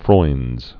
(froindz)